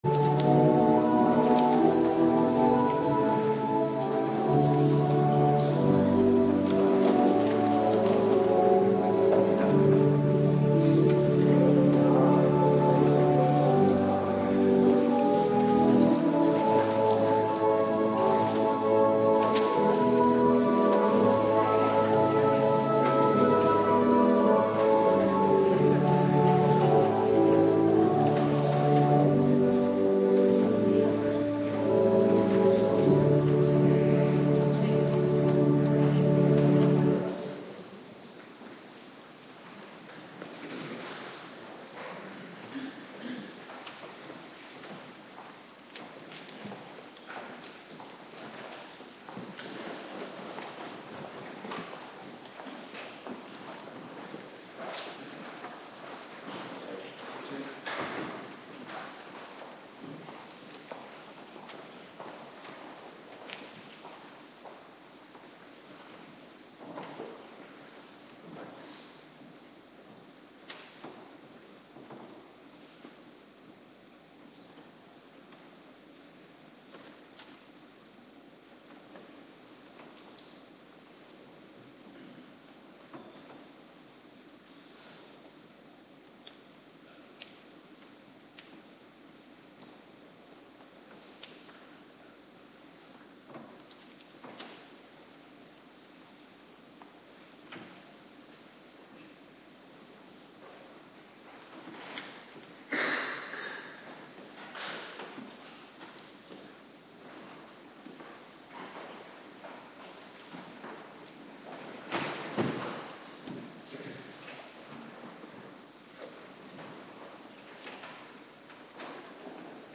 Avonddienst - Cluster 3